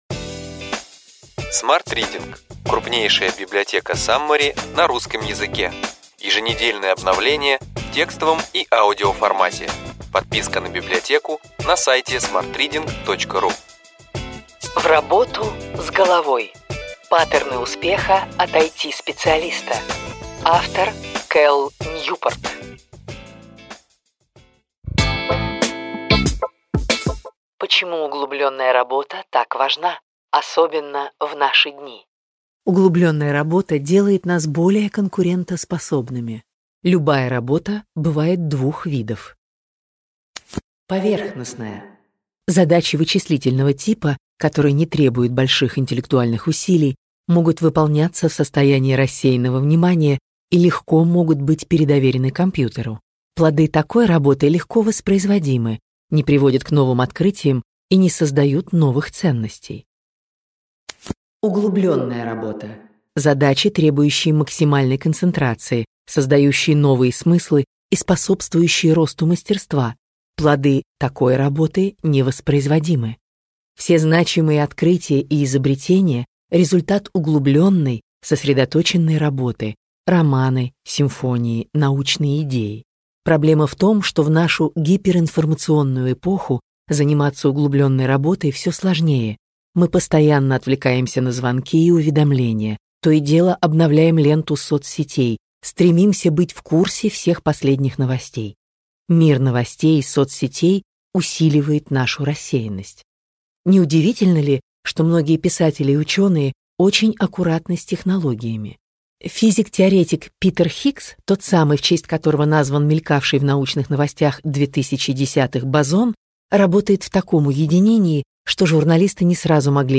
Аудиокнига Ключевые идеи книги: В работу с головой. Паттерны успеха от IT-специалиста.